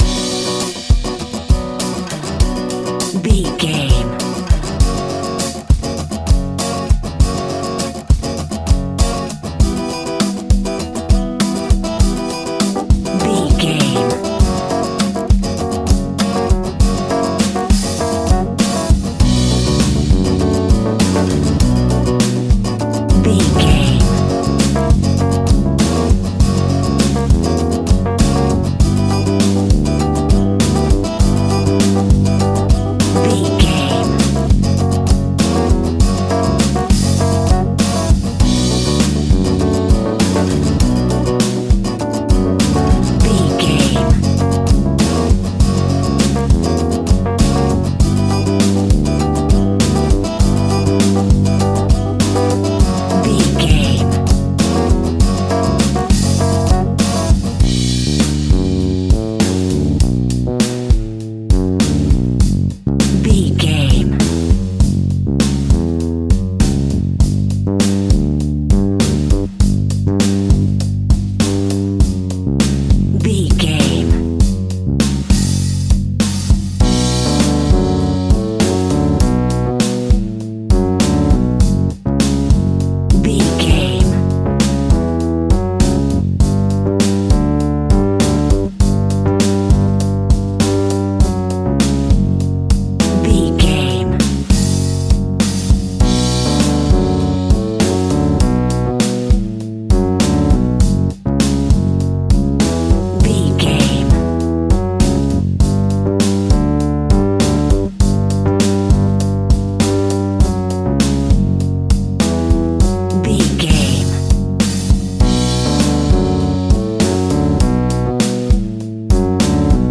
Aeolian/Minor
flamenco
maracas
percussion spanish guitar
latin guitar